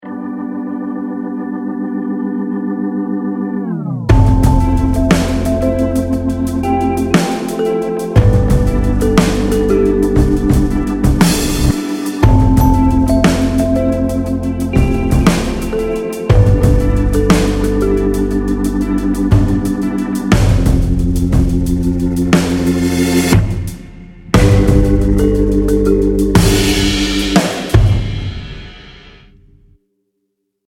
Retro Warm